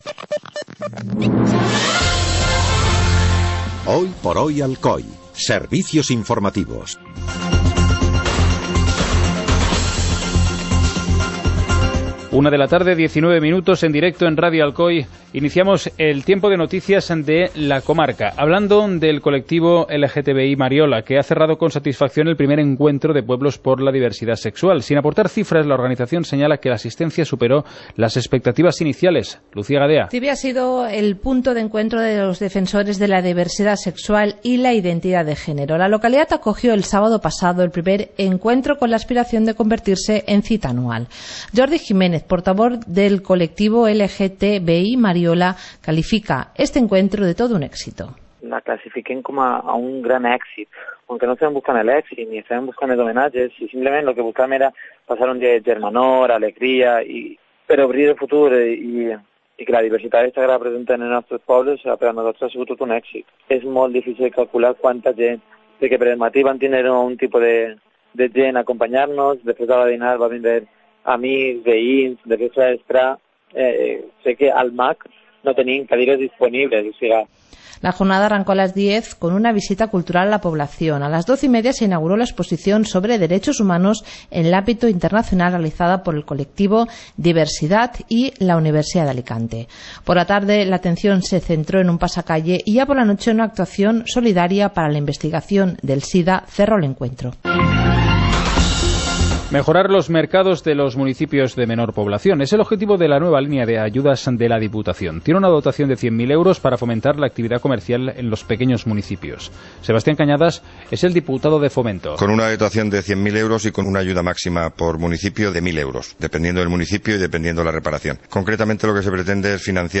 Informativo comarcal - lunes, 13 de junio de 2016